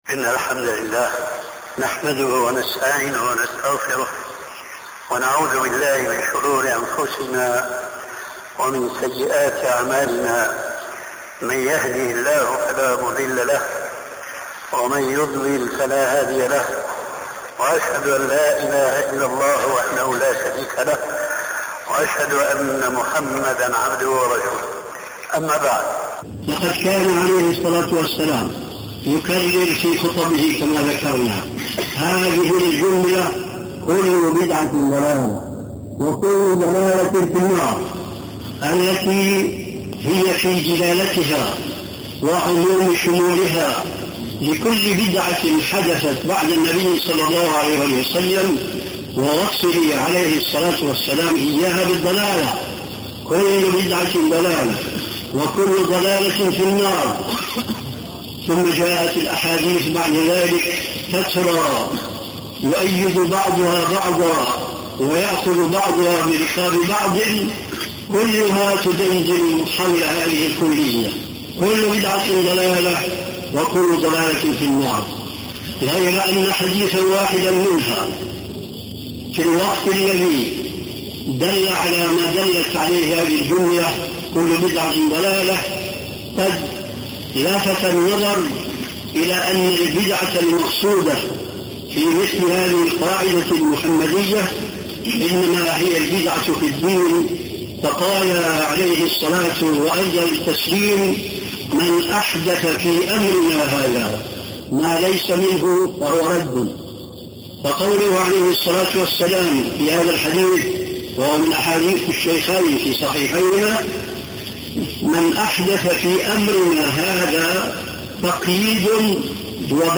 شبكة المعرفة الإسلامية | الدروس | السنة والبدعة والشبهات |محمد ناصر الدين الالباني